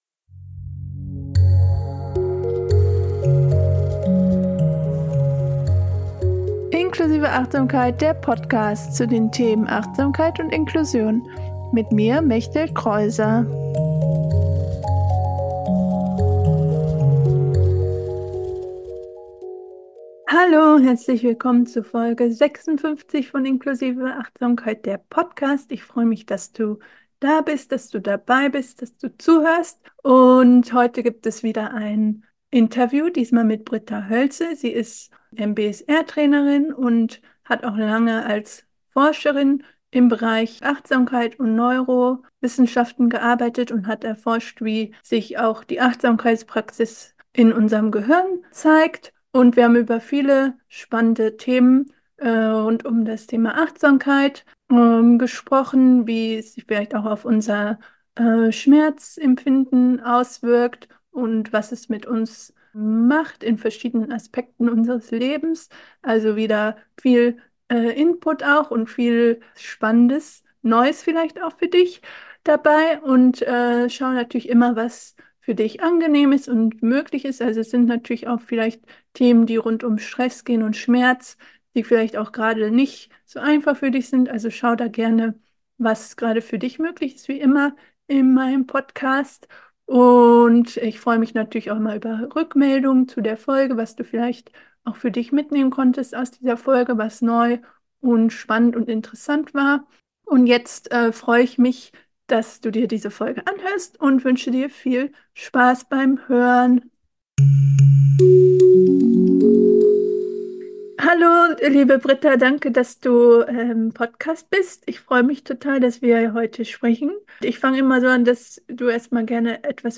56 - Achtsamkeit und Neurowissenschaft: Die Effekte von Meditation auf unser Gehirn - Interview